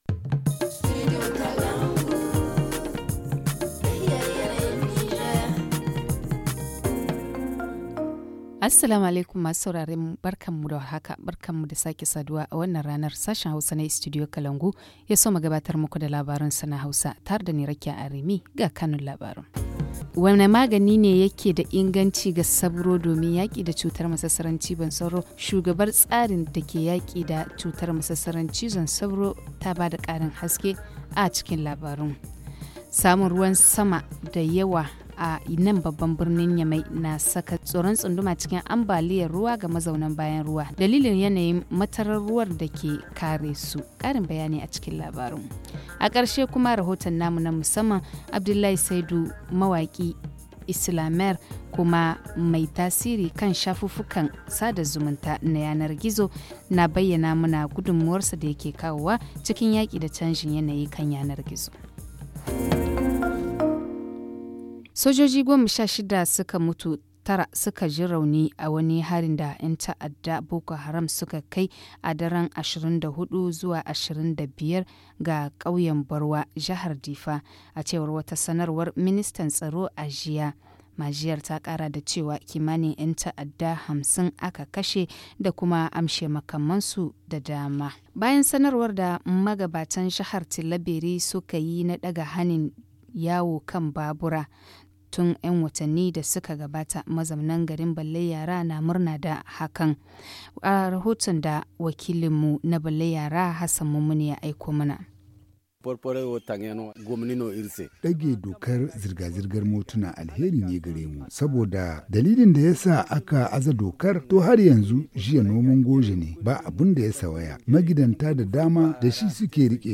Le journal du 26 août 2021 - Studio Kalangou - Au rythme du Niger